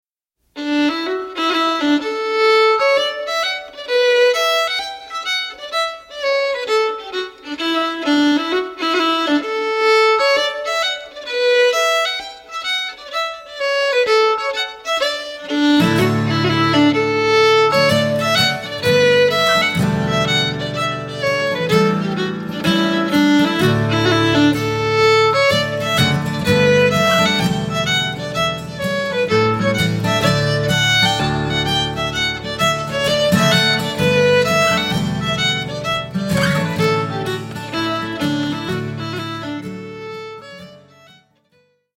hornpipe
fiddle & guitar duo
Scots-Irish & Americana